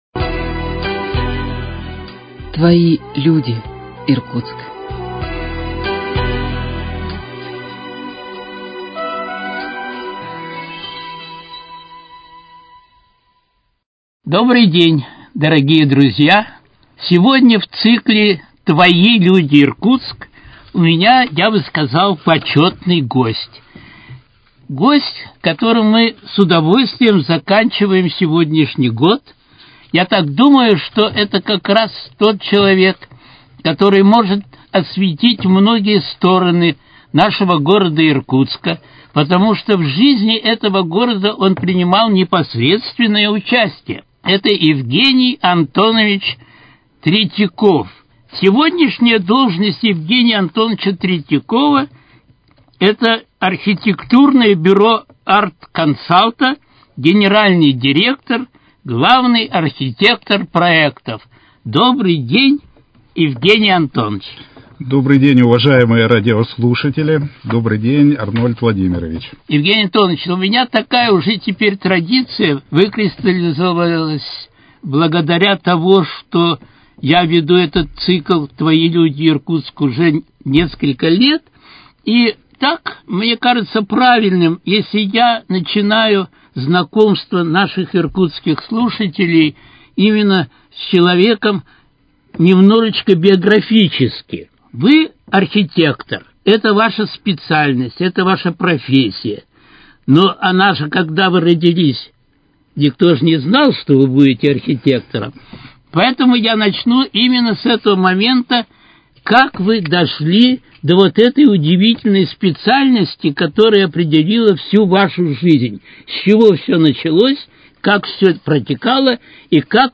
Твои люди, Иркутск: Беседа